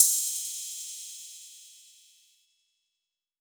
Crashes & Cymbals
MB Crash (3).wav